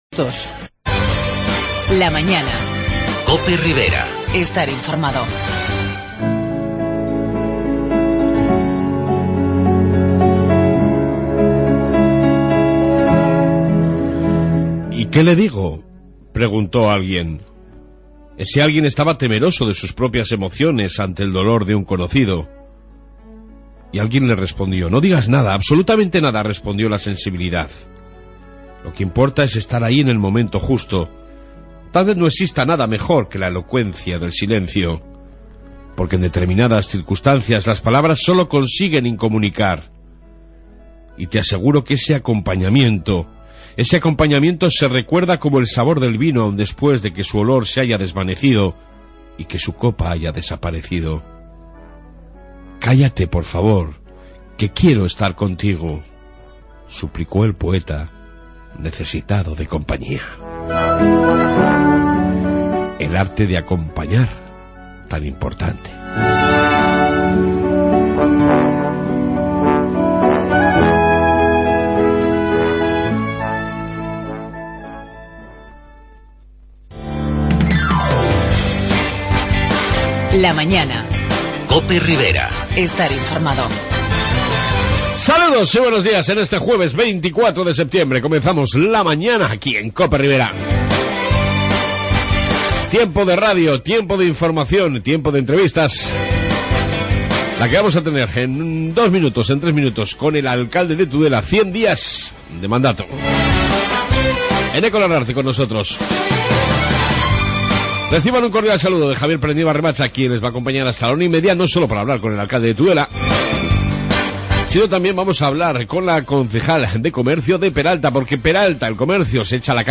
AUDIO: Reflexión diaria y amplia entrevista sobre los primeros 100 días de gobierno con el Alcalde Eneko Larrarte